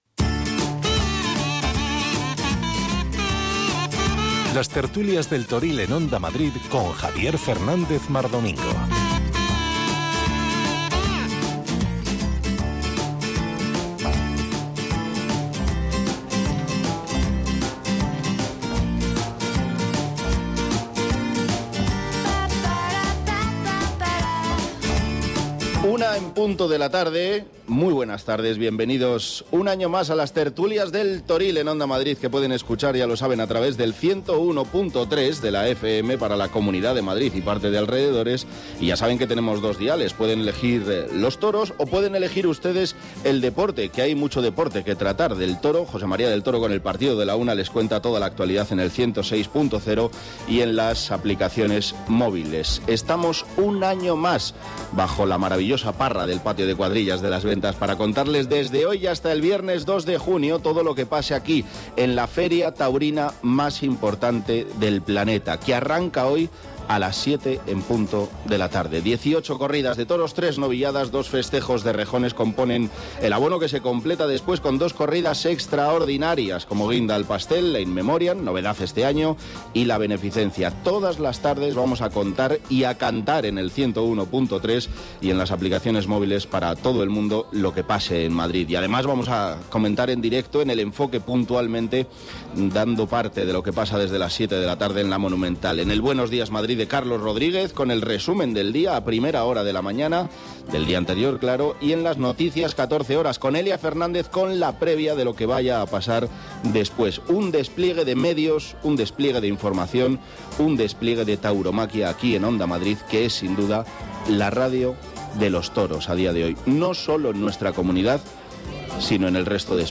Cada día desde la plaza de toros de las Ventas Las Tertulias del Toril en directo para contar y analizar lo que haya pasado en la Feria de San Isidro y para analizar lo que vaya va a pasar ese mismo día